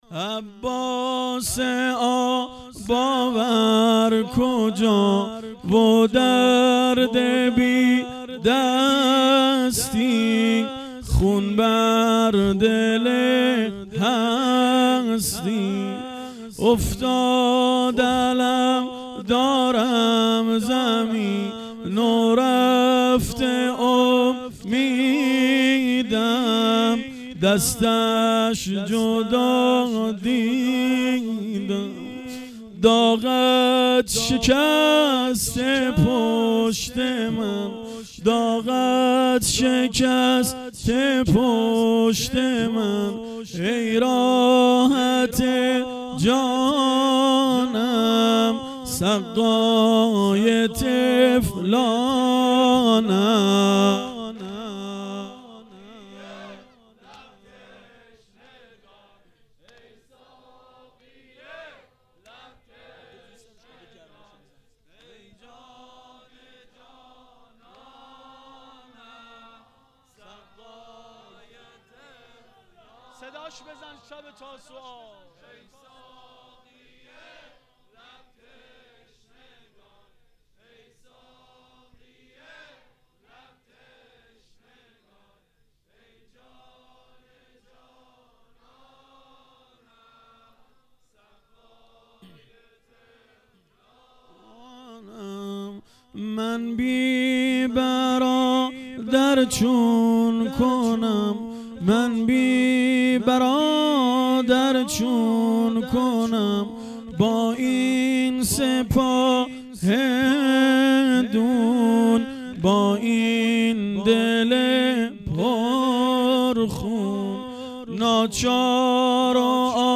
واحد سنتی شب نهم